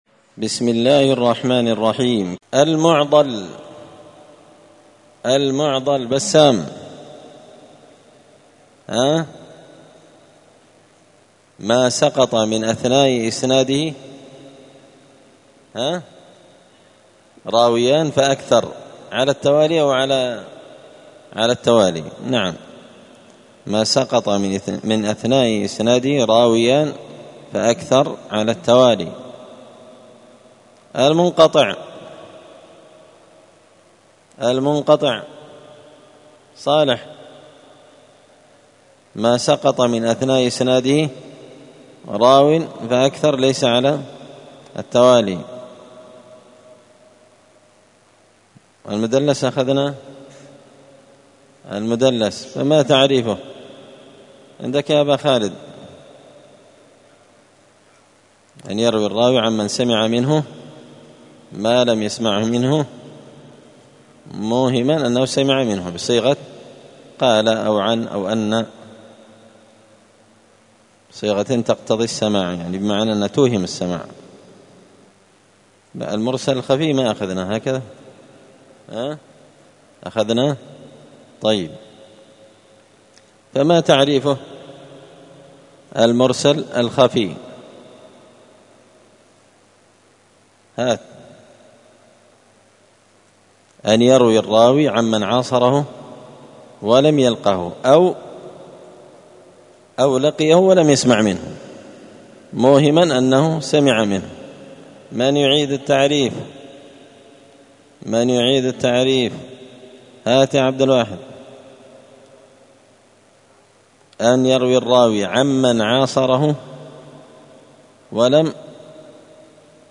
تعليق وتدريس الشيخ الفاضل: